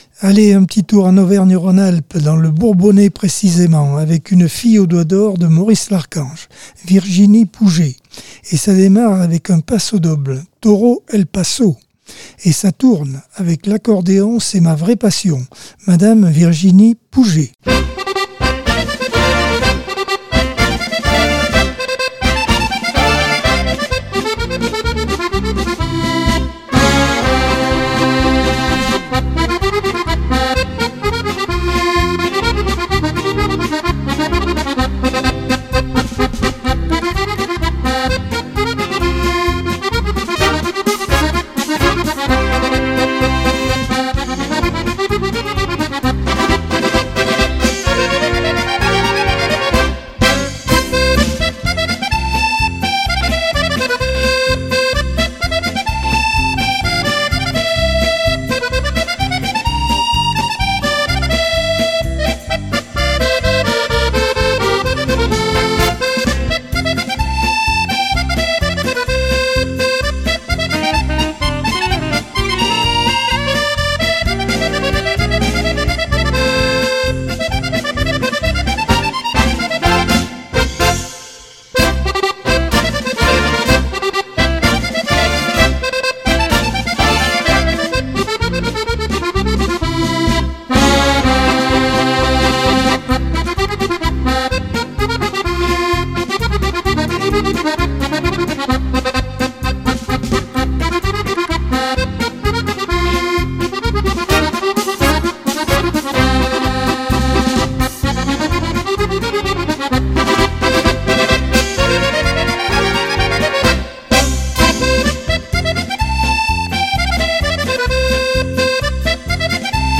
Accordeon 2024 sem 28 bloc 2 - Radio ACX